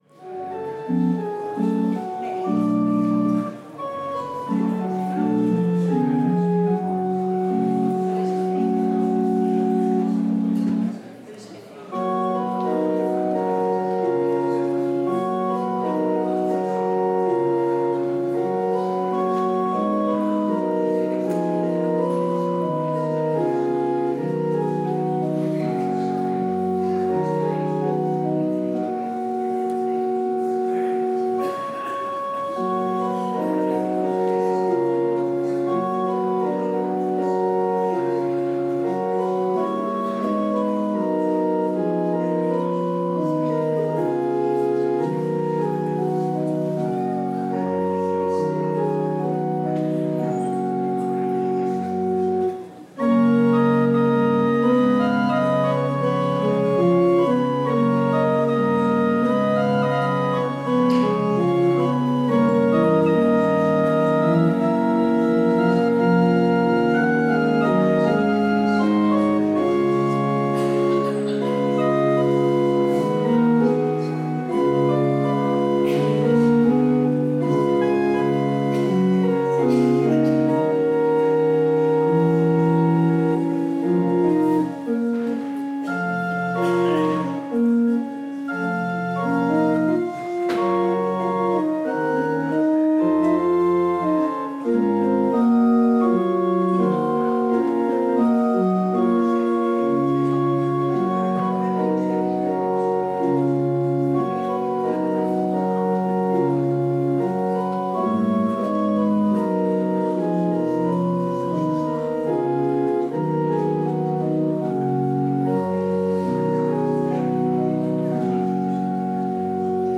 Live dienst Bloemendaal Overveen
In de Dorpskerk in Bloemendaal
Het orgel